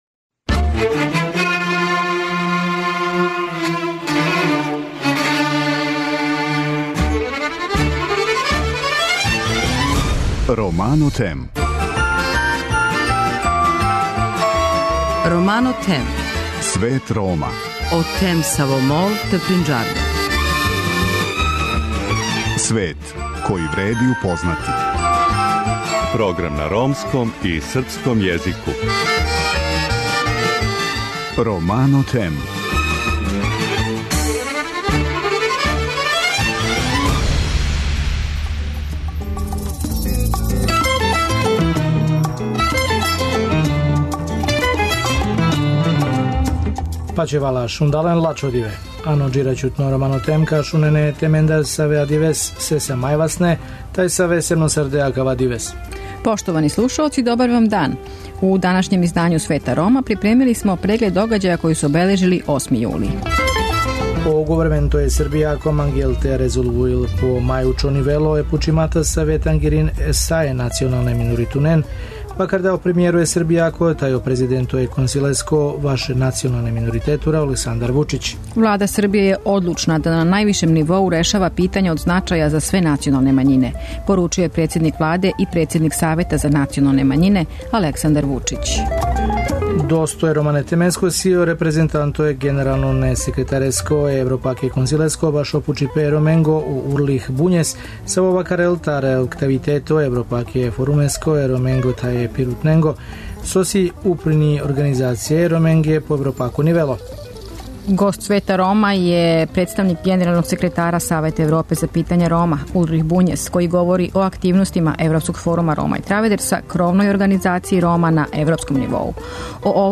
Радио Београд 1, 19.20 Слика са насловне стране: Тањуг